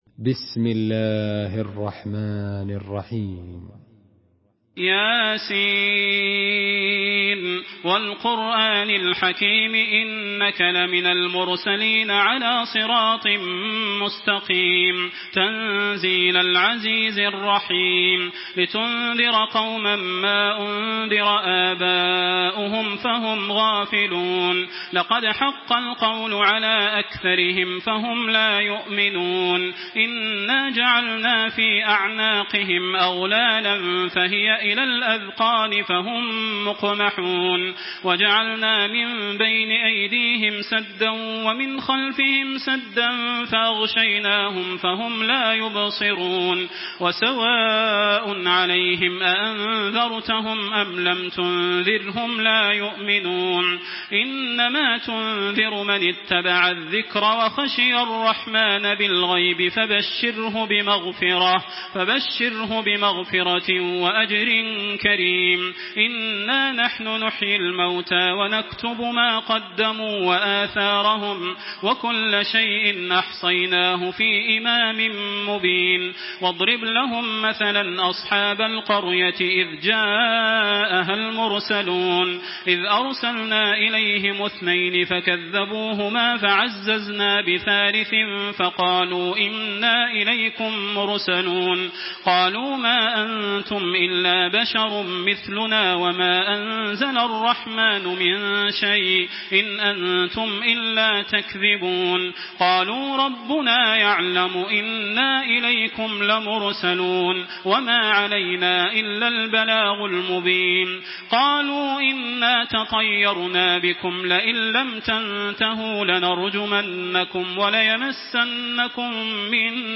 Surah Yasin MP3 in the Voice of Makkah Taraweeh 1426 in Hafs Narration
Murattal Hafs An Asim